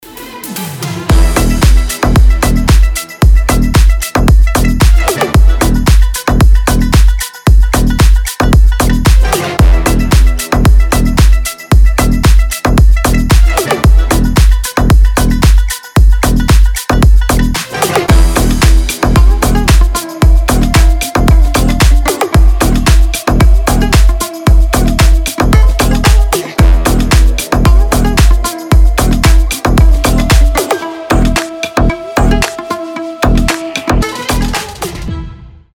басы
восточные
house
индийские
Индийский мотив